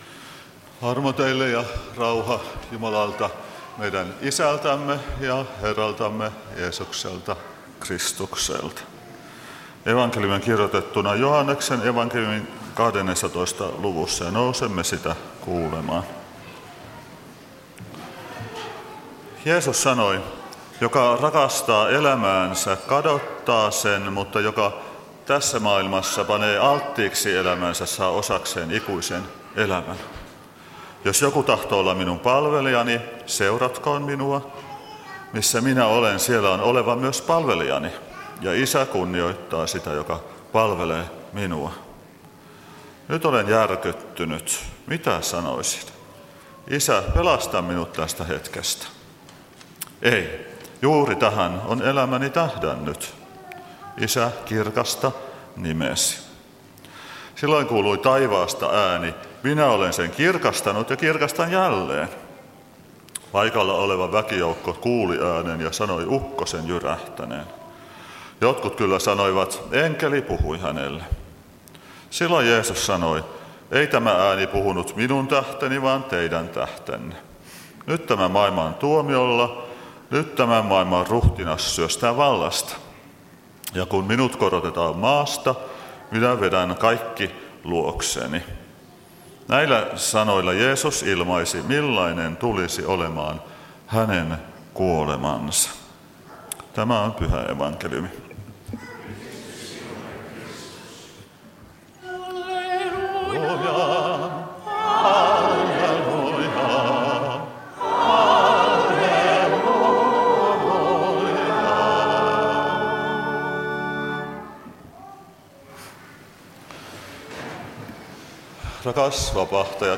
Kokoelmat: Tampereen Luther-talo